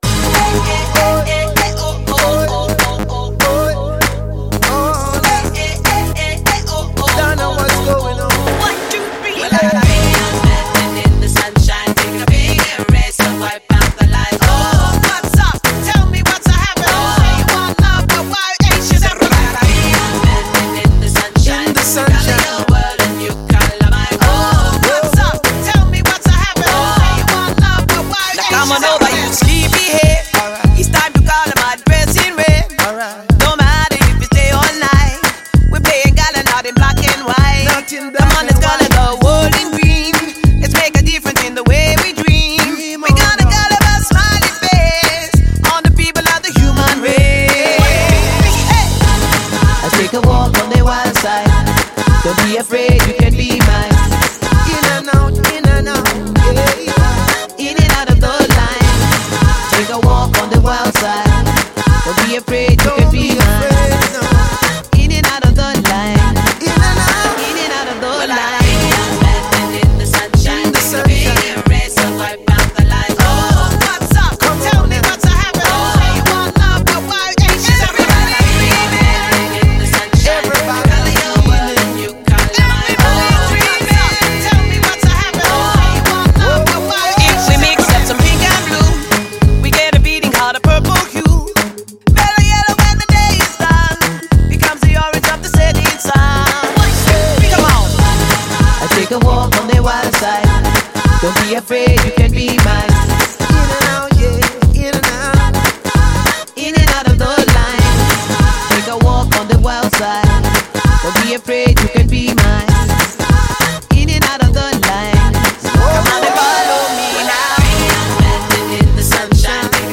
Feel-good music if there ever was such thing.